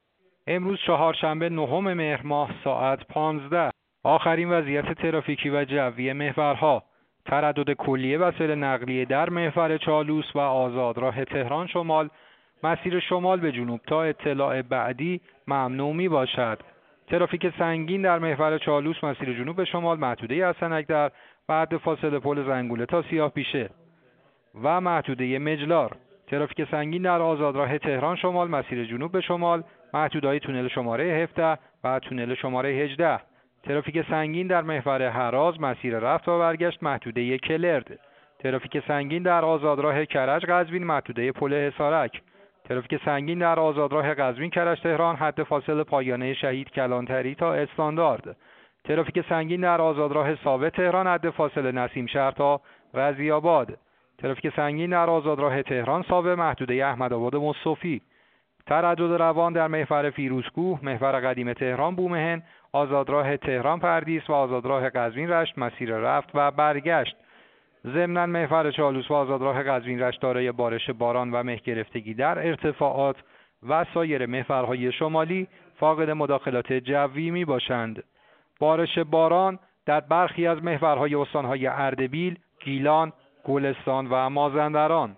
گزارش رادیو اینترنتی از آخرین وضعیت ترافیکی جاده‌ها ساعت ۱۵ نهم مهر؛